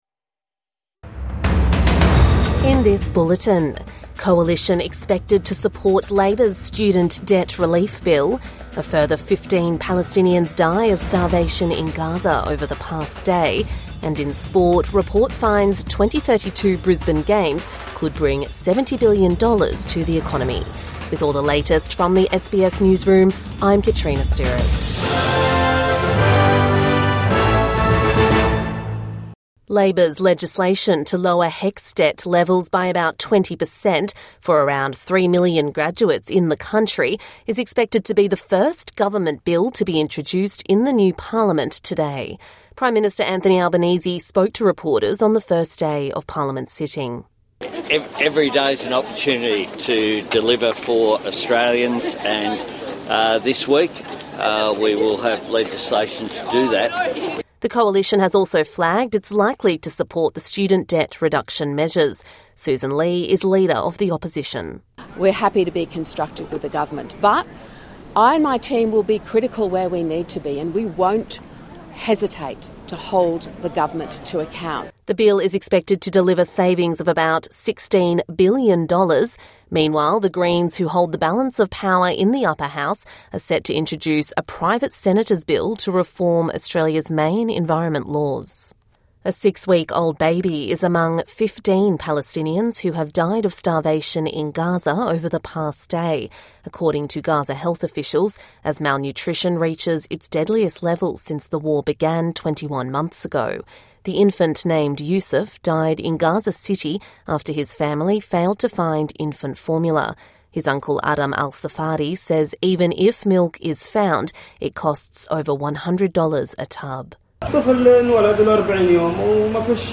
Government's first target is HECS debt | Morning News Bulletin 23 July 2025